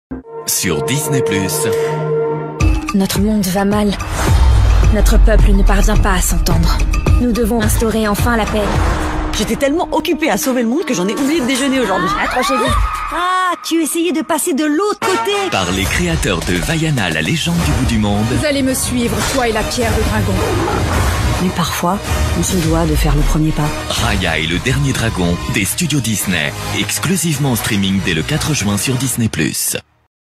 comédien voix off jeunesse - Comédien voix off
1. RAYA & LE DERNIER DRAGON jeunesse 0:30